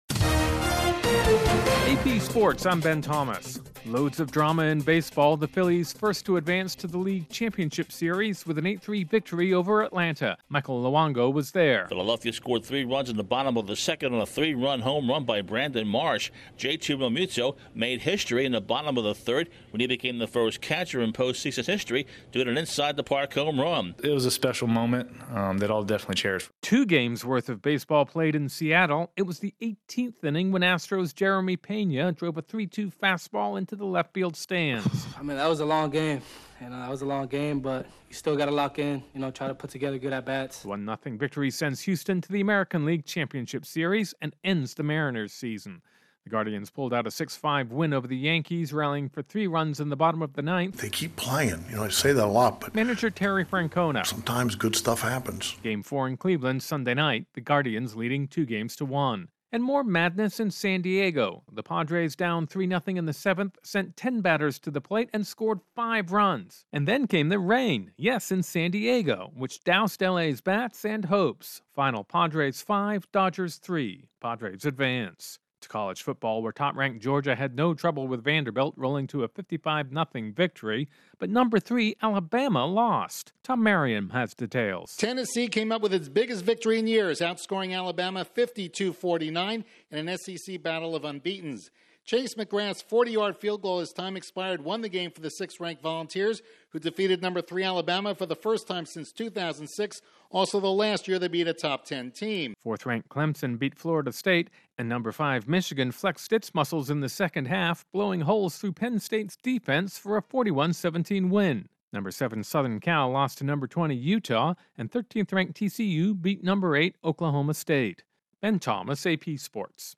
A dramatic day on the diamonds see three of baseball playoff series completed, while there's likely to be some significant change in the AP Top 25 college football rankings after Saturday's action. AP correspondent